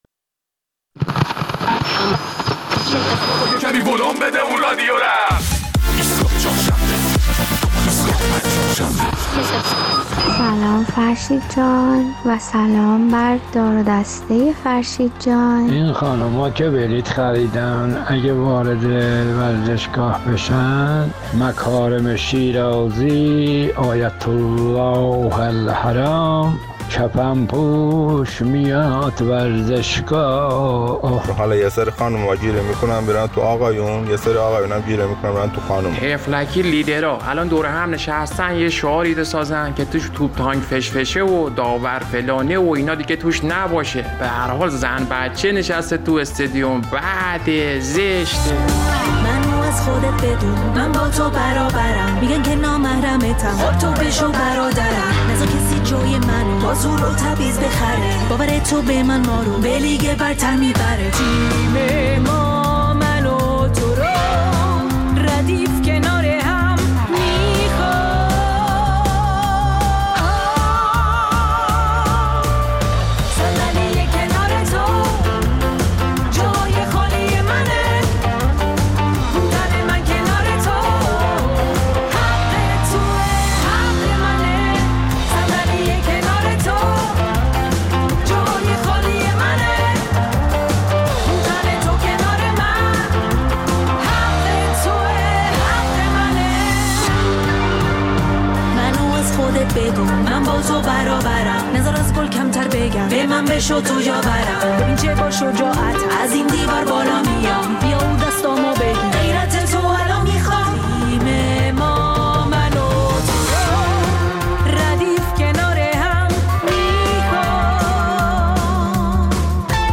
در این برنامه نظر شنوندگان ایستگاه فردا را در مورد اختصاص جایگا‌‌ه‌هایی محدود به خانم‌ها در ورزشگاه آزادی برای تماشای بازی ایران و کامبوج می‌شنویم.